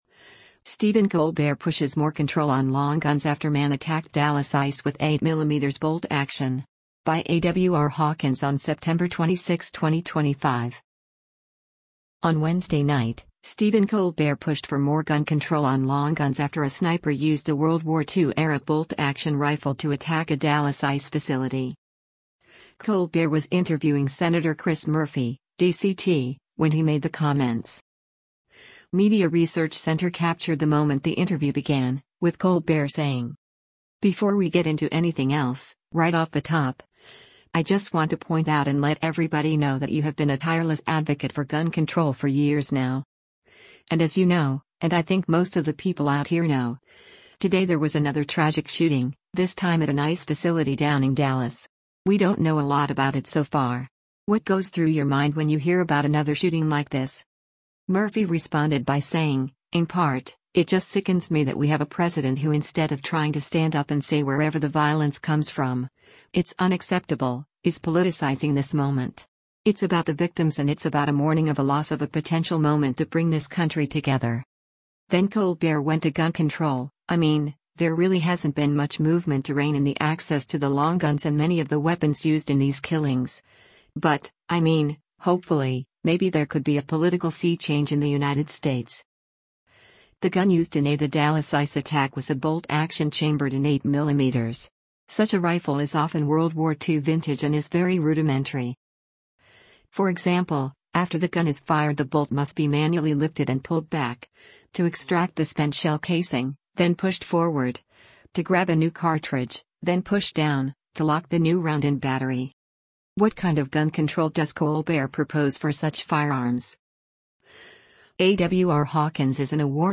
Colbert was interviewing Sen. Chris Murphy (D-CT) when he made the comments.